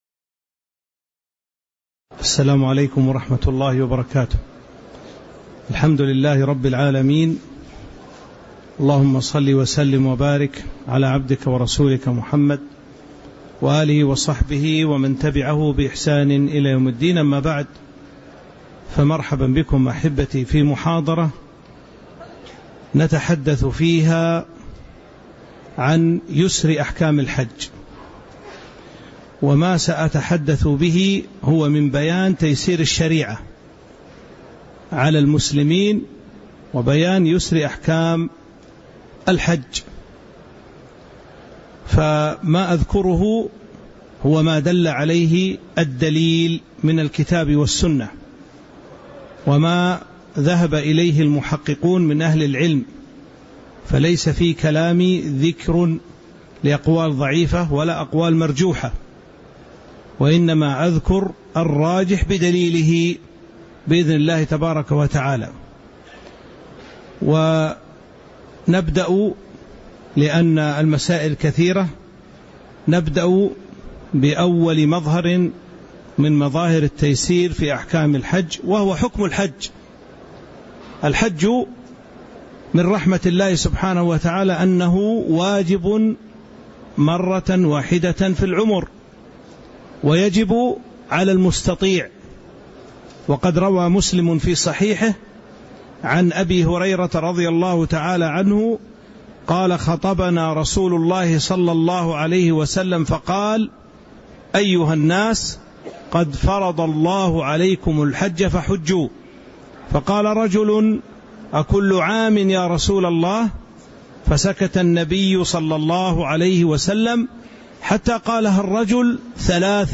تاريخ النشر ٢٩ ذو القعدة ١٤٤٥ هـ المكان: المسجد النبوي الشيخ